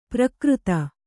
♪ prakřta